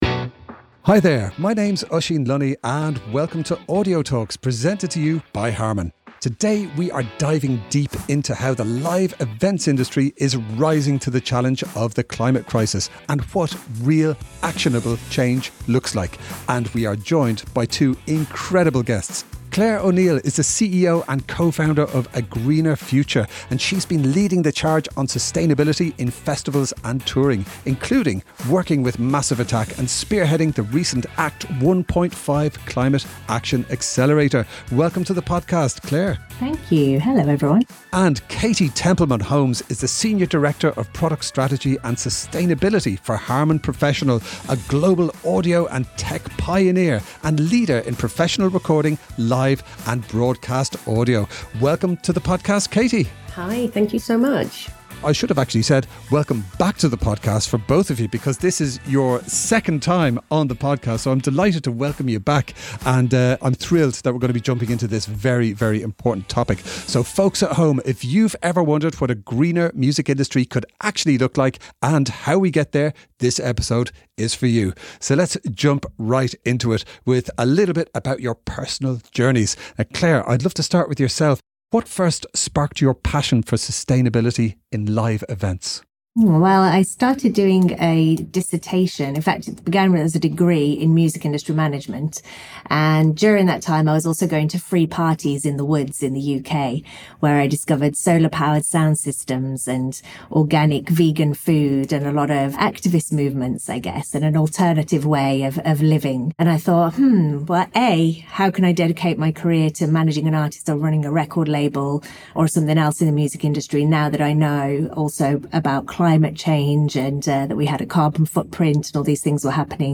Tune in for some rhythm, sound, and laughter!